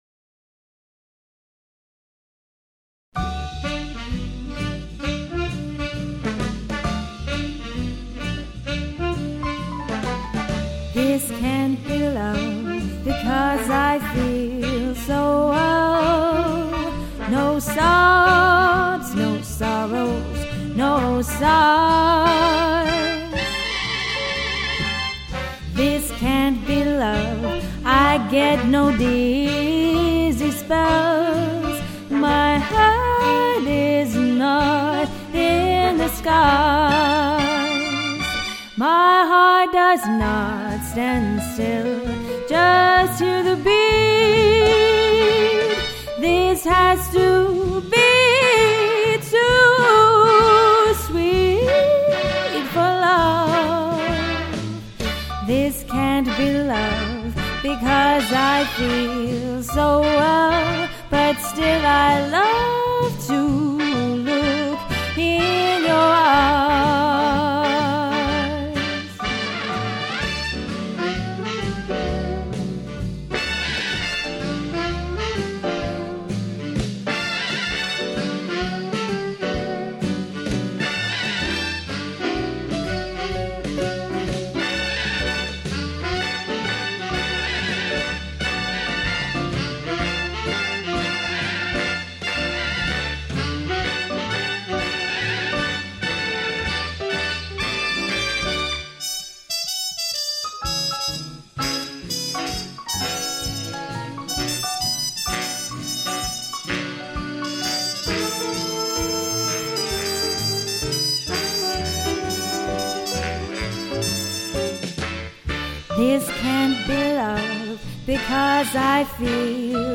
weddings, solo singer, vintage events